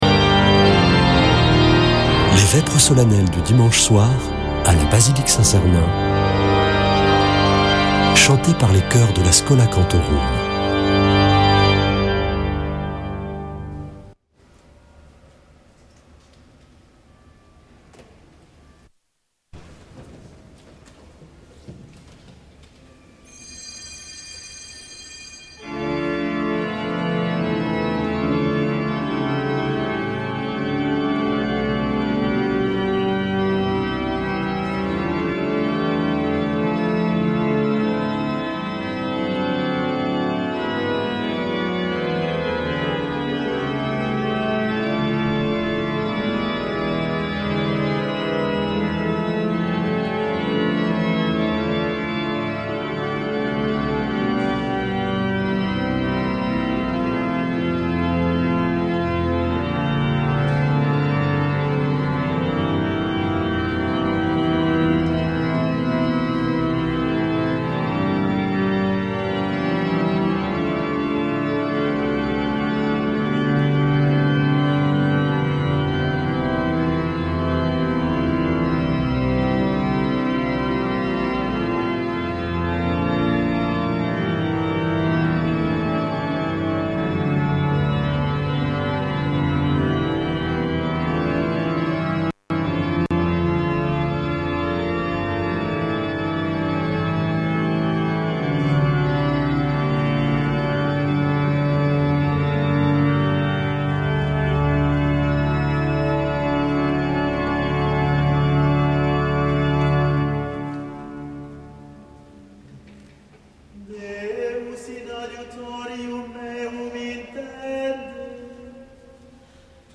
Vêpres de Saint Sernin du 02 févr.